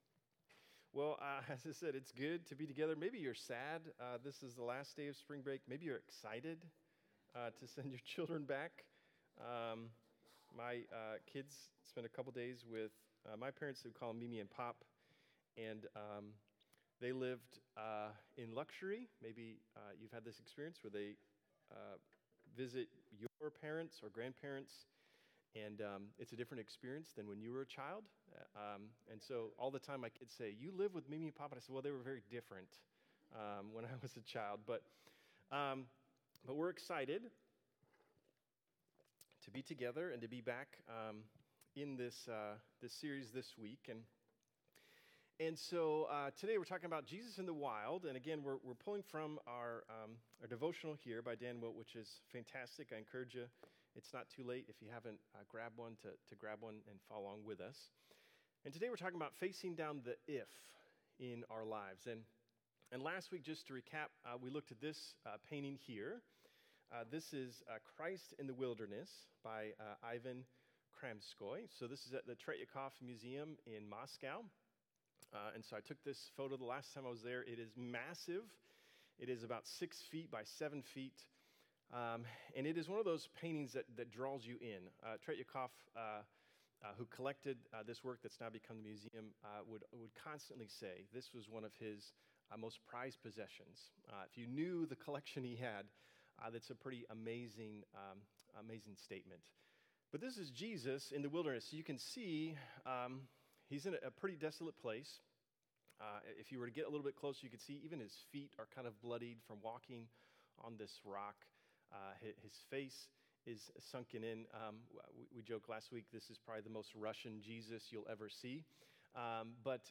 Sermons | Bridge City Church